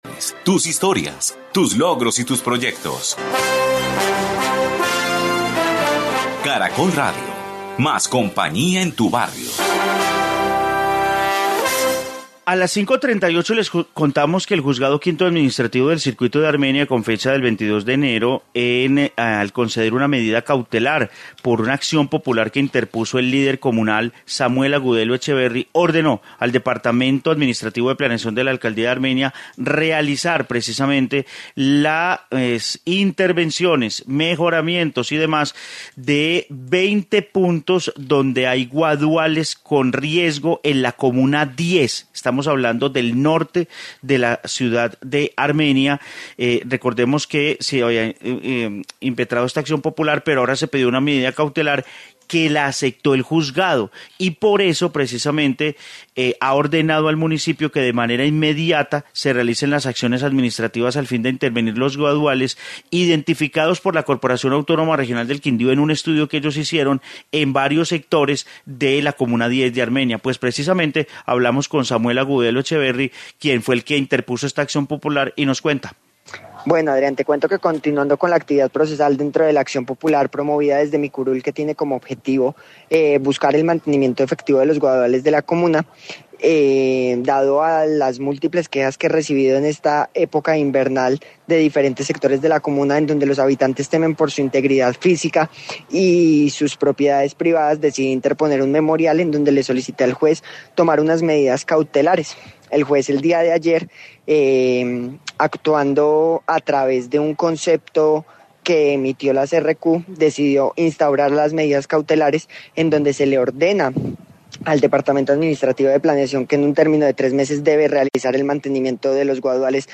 Informe guaduales en Armenia